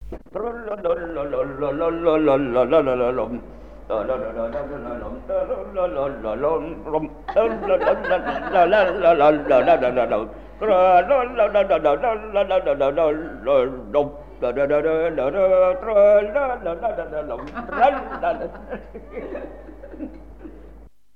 Chants brefs - A danser
Pièce musicale inédite